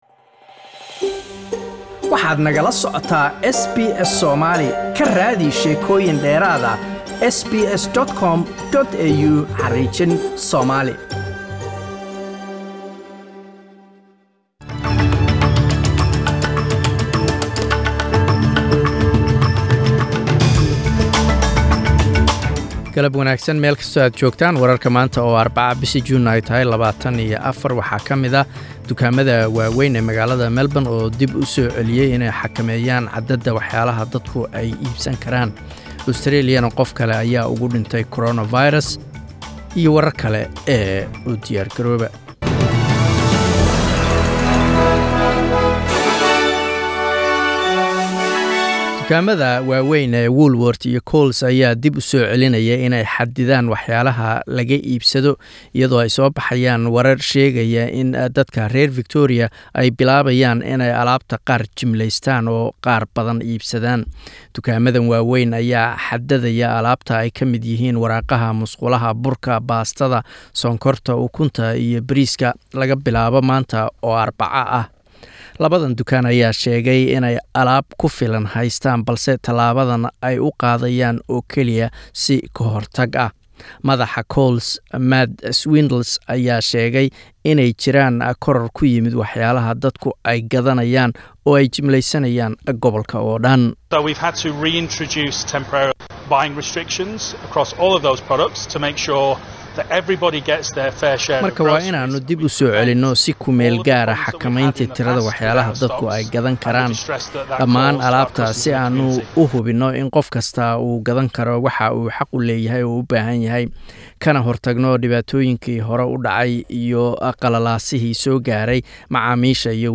Wararka SBS Somali Arbaco 24 June